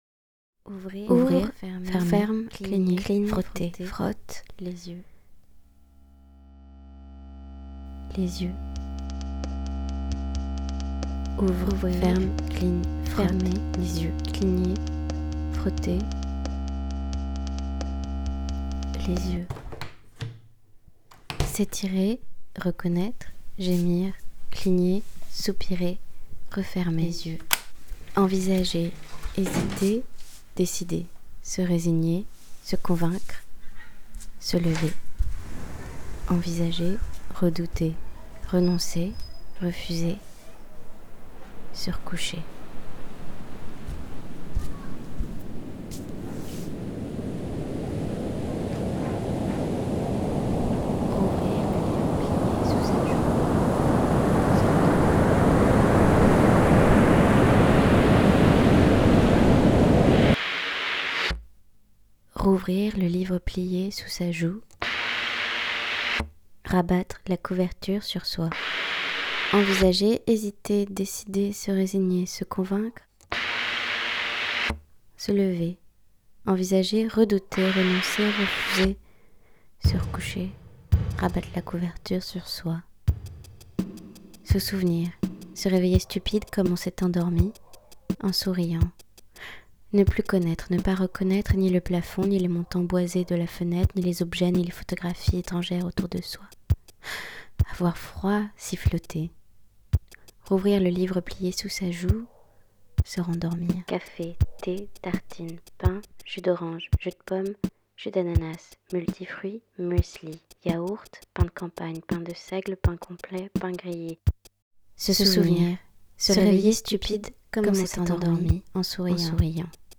Émission de création sonore. En utilisant la thématique mensuelle de la récolte de sons comme contrainte créative, fabrication de courts univers sonores variés.